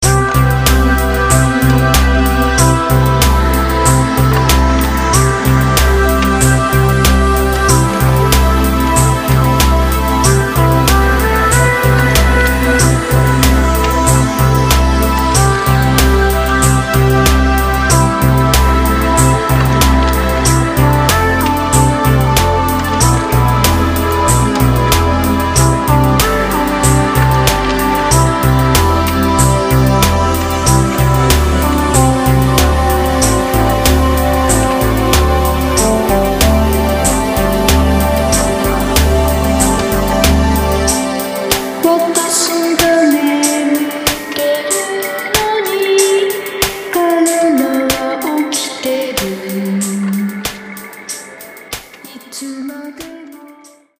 Cyberpunk + Industrial + Goth + Deathrock